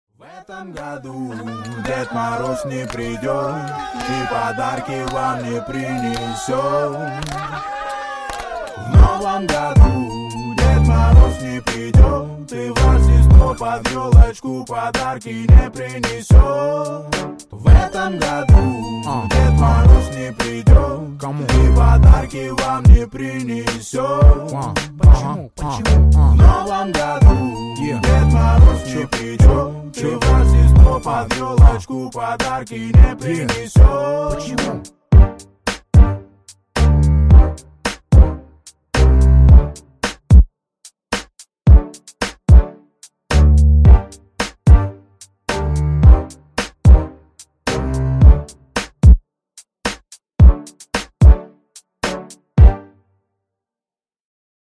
• Качество: 128, Stereo
Хип-хоп
грустные
русский рэп